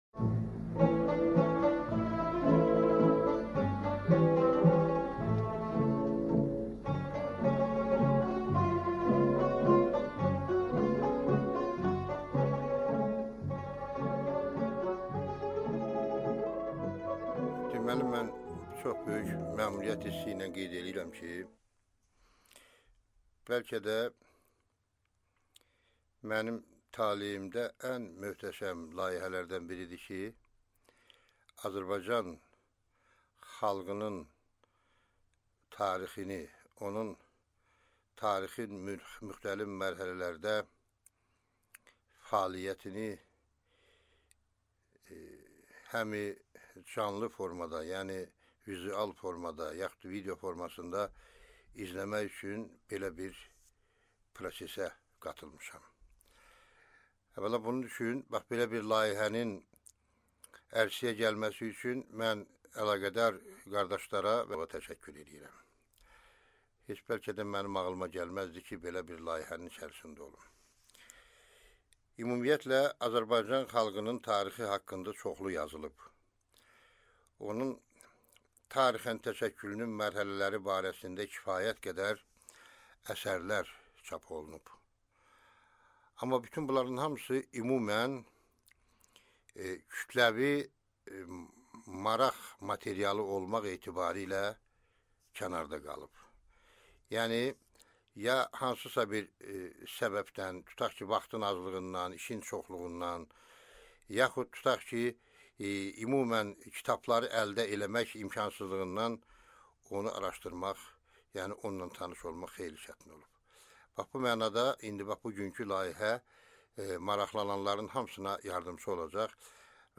Аудиокнига Azərbaycan xalqının etnogenezi | Библиотека аудиокниг